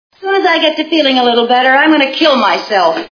I Love Lucy TV Show Sound Bites